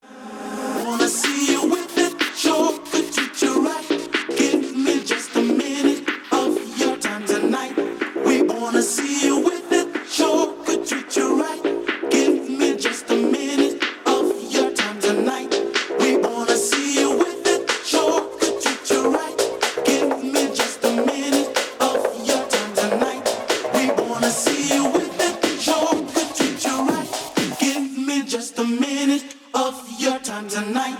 • Качество: 180, Stereo
громкие
house
клубная музыка